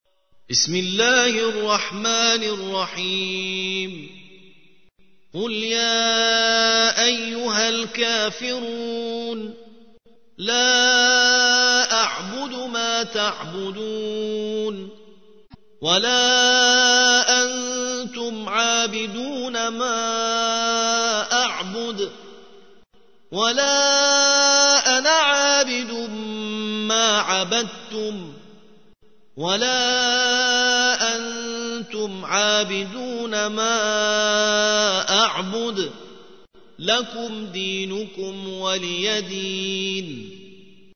109. سورة الكافرون / القارئ